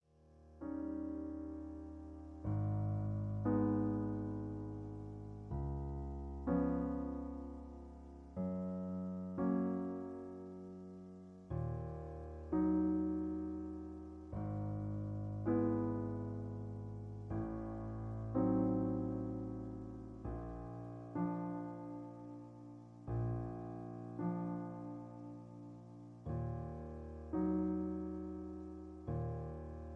In E flat. Piano Accompaniment